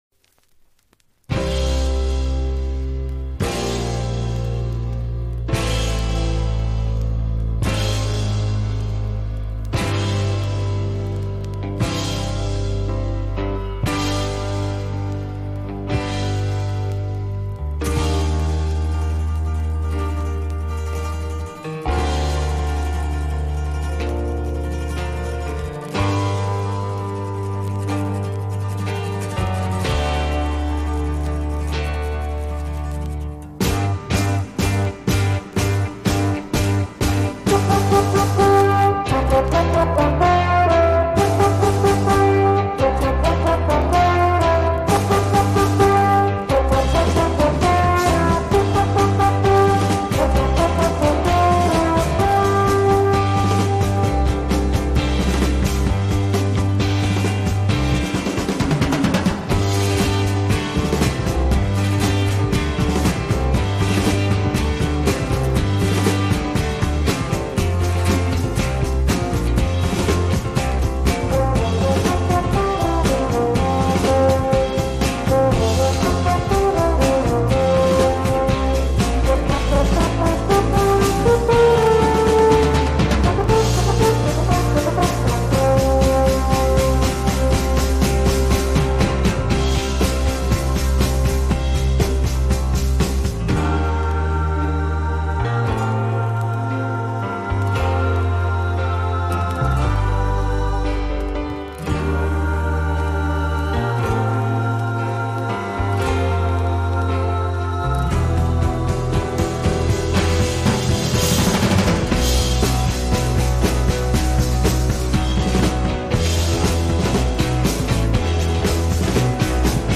Vinyl Full Album
Dual CS505-4 (Upgrade) w. Goldring 1012GX (MM cartridge)